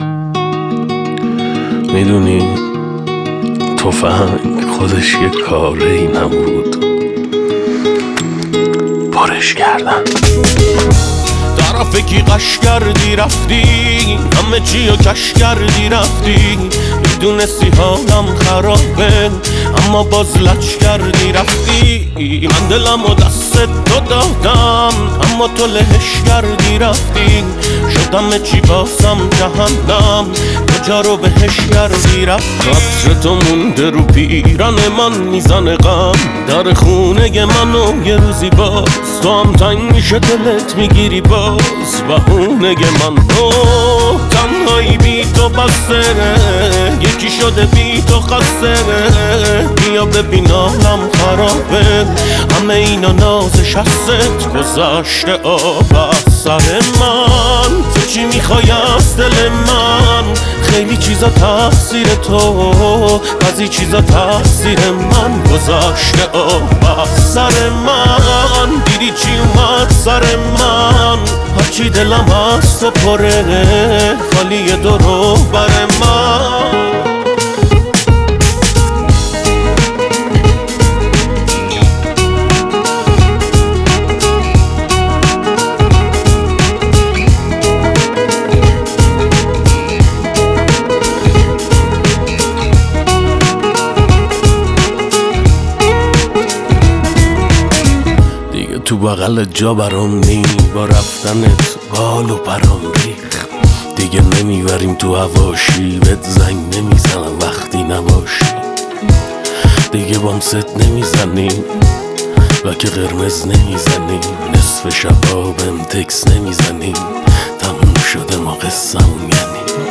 آهنگ جدید